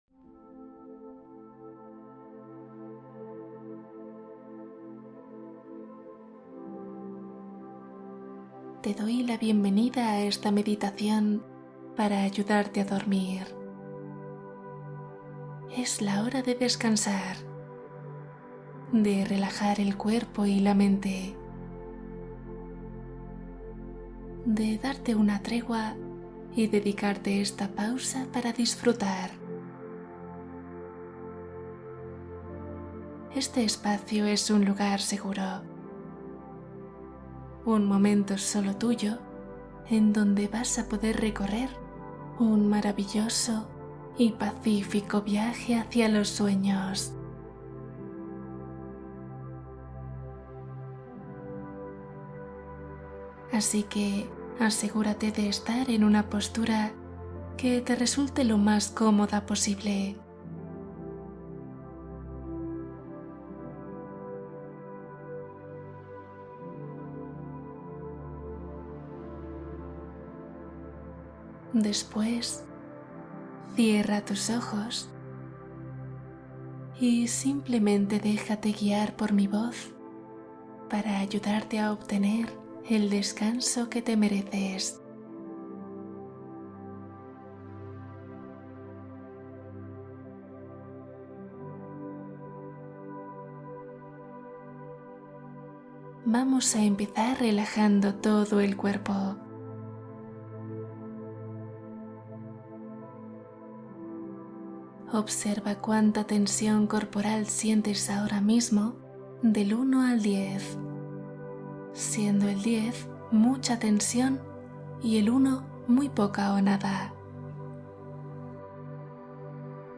Meditación del sueño ❤ Cuento y relajación para calmar insomnio